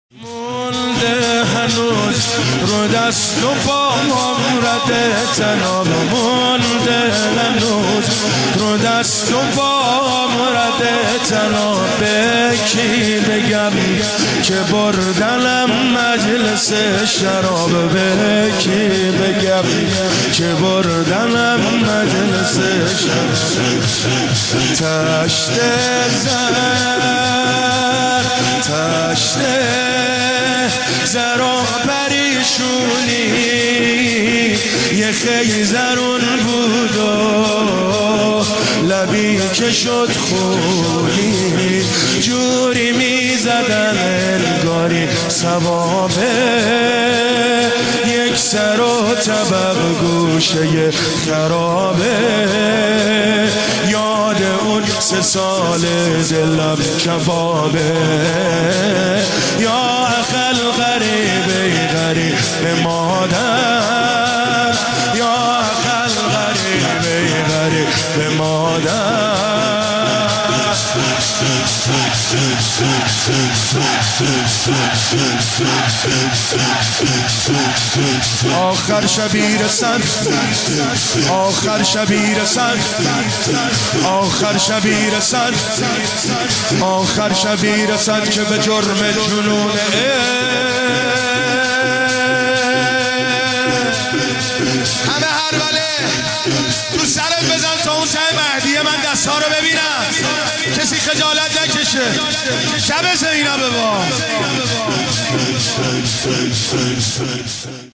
پانزدهمین اجتماع مدافعان حرم در مهدیه تهران